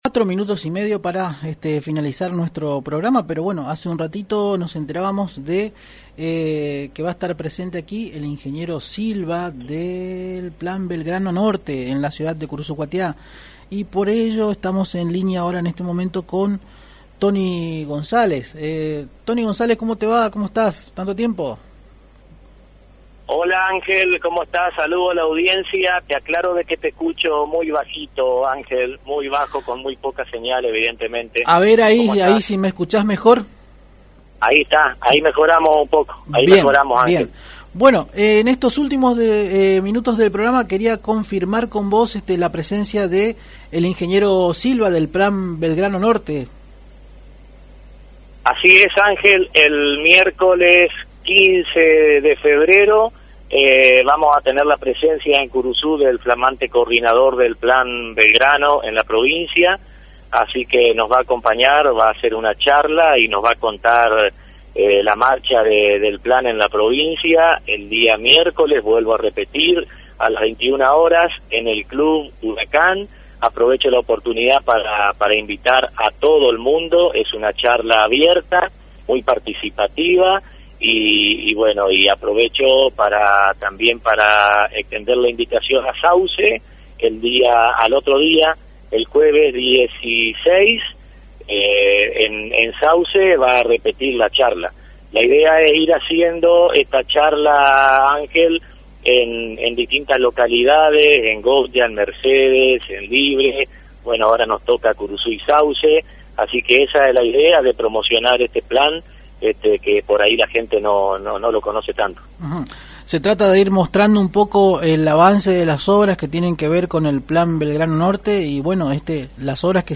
(Audio) Tony González, director regional del Ministerio de Trabajo de Nación dialogó con Agenda 970 y confirmó sobre la llegada a Curuzú del flamante coordinador del Plan Belgrano Norte el próximo miércoles 15 de febrero.